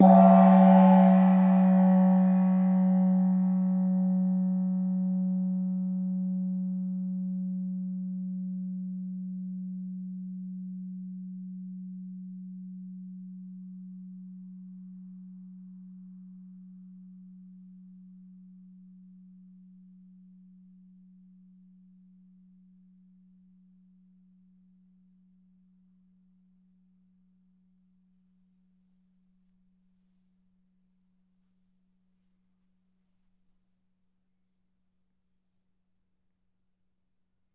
中等大小的铜锣，调至F，"铜锣打得很响。
描述：工作室录制中等大小的Gong调到F＃。使用ZOOM H4以96 kHz / 24位分辨率录制。
标签： 打击乐 金属 特写
声道立体声